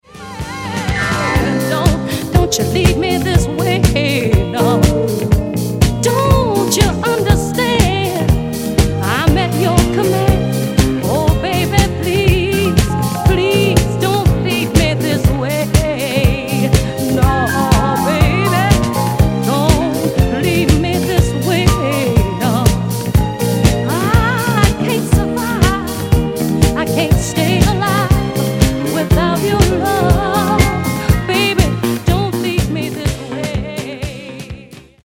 Genere:   Disco | Funky | Soul |